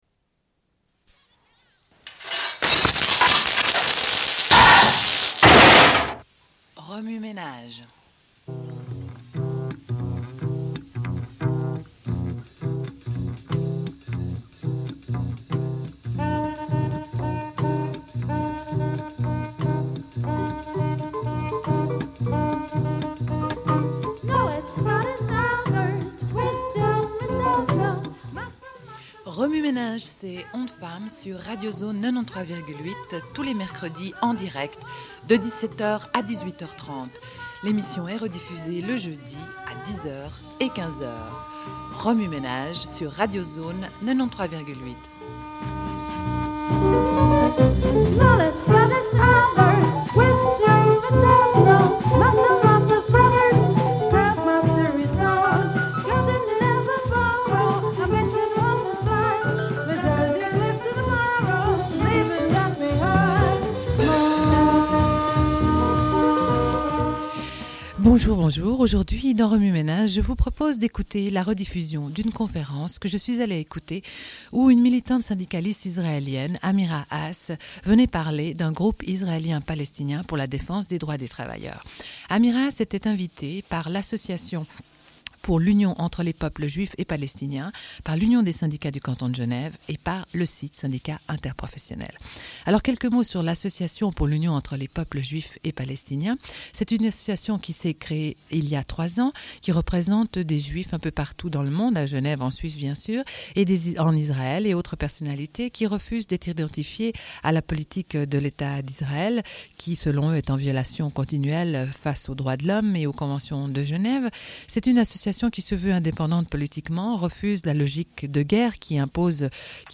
Une cassette audio, face A00:31:25
Sommaire de l'émission : rediffusion d'une conférence donnée par Amira Hass, militante syndicaliste israélienne, invitée par l'Association pour l'Union des peuples juifs et palestiniens, l'Union des syndicats du Canton de Genève et par le syndicat SIT. Elle parle du Workers' Hotline, groupe israélo-palestinien pour la défense des droits des travailleurs.